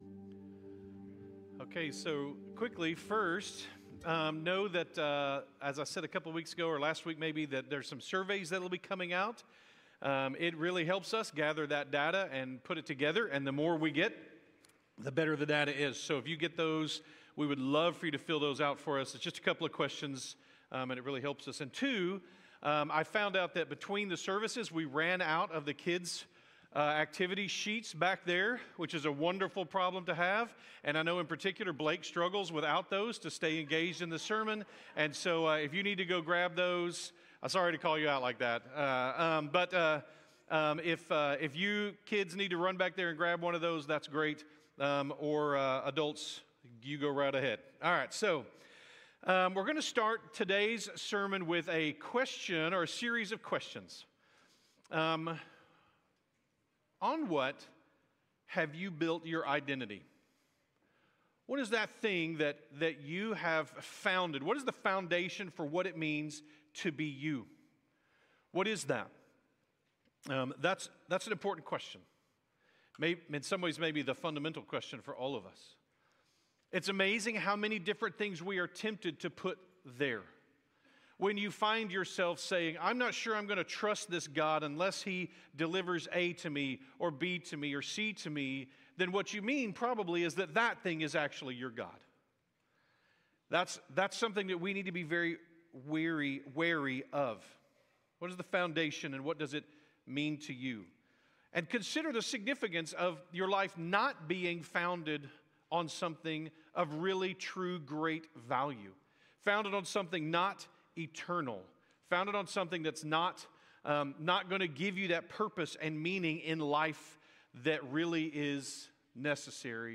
by South Spring Media | Oct 19, 2025 | 2025 Sermons, Isaiah, Isaiah Series | 0 comments